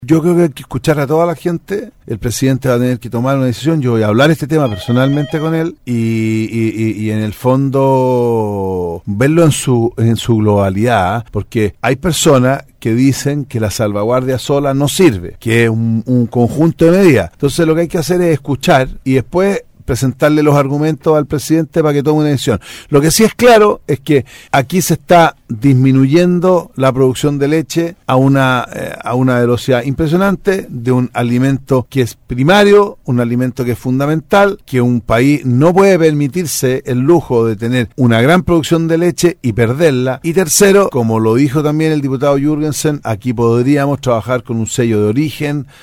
Ossandón quien visitó Osorno y fue entrevistado en el programa “Contigo en SAGO” hizo hincapié que Chile ha pasado de país exportador de leche a importador y estima que podría haber dumping, aunque también cree que no se puede demonizar los productos lácteos.